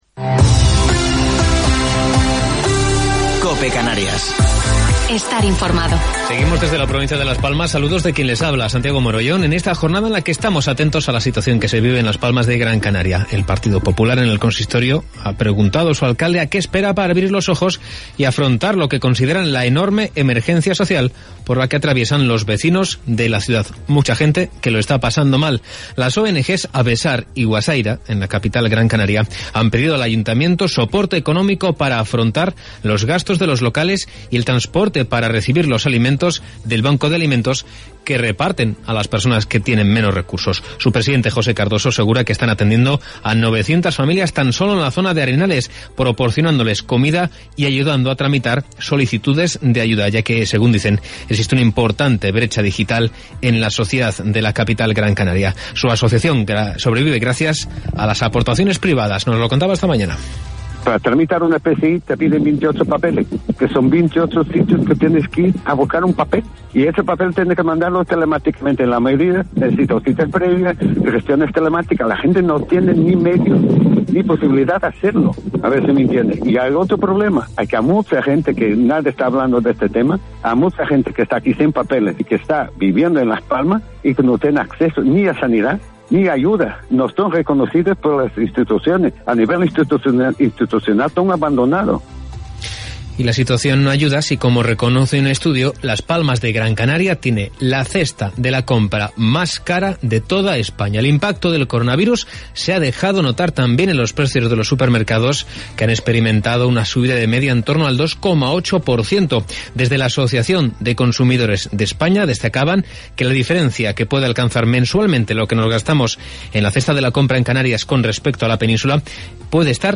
Informativo local 27 de Octubre del 2020